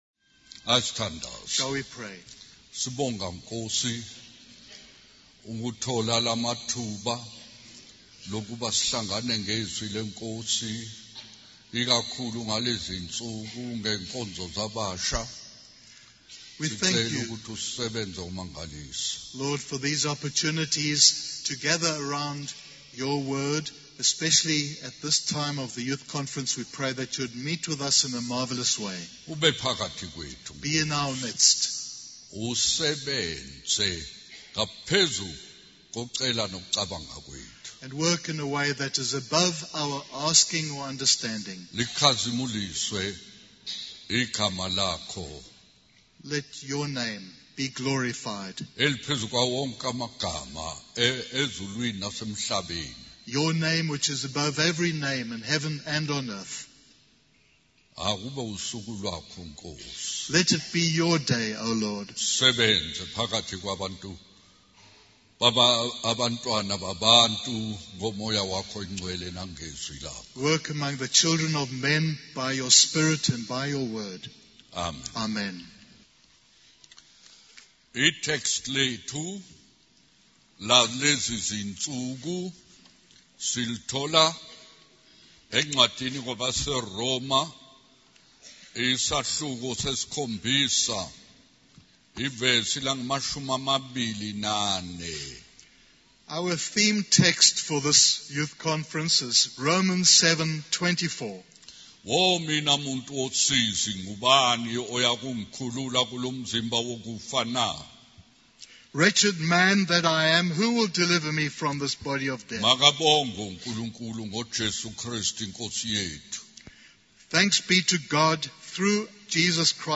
The sermon concludes with a prayer of surrender and a call to Christians to heed the message of the man's story.